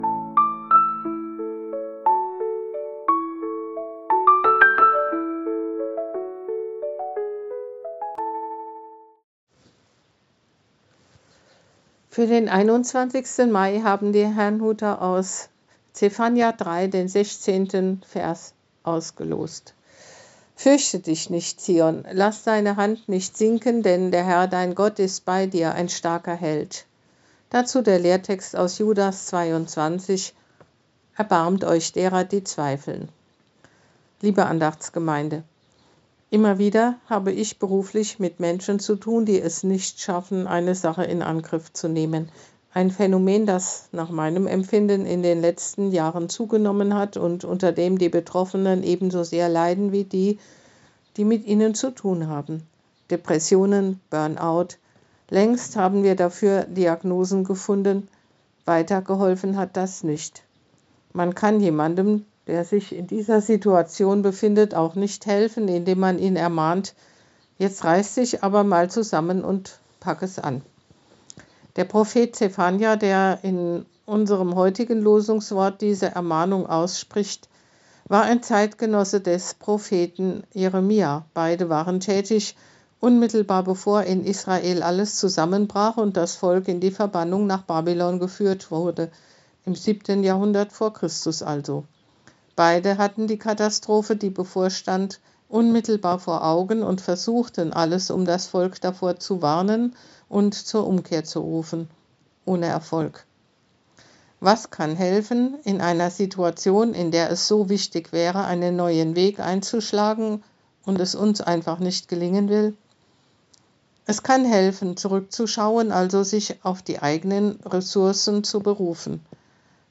Losungsandacht für Mittwoch, 21.05.2025